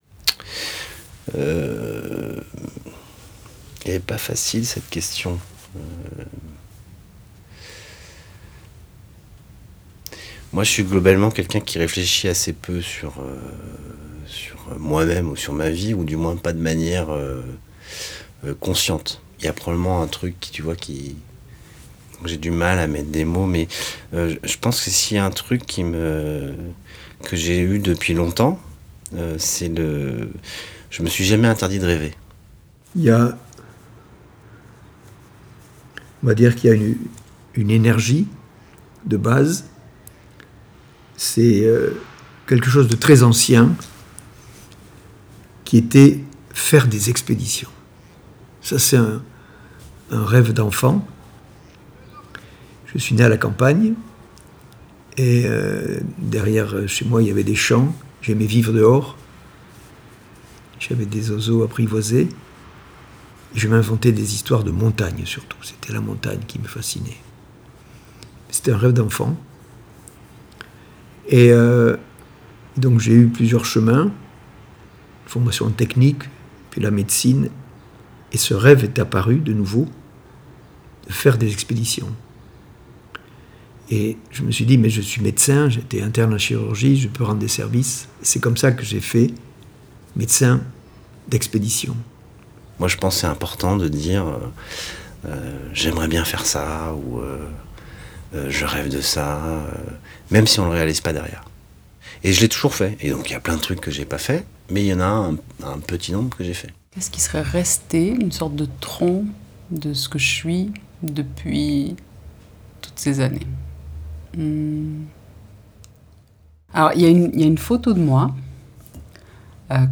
Montages d’extraits d’interviews réalisées pour le spectacle CE QU’IL NOUS FAUT !
Ce que vous allez entendre est en cours de montage, et non mixé.
Où les paroles se croisent, les personnages se répondent, et s’incarnent dans des haut-parleurs personnages.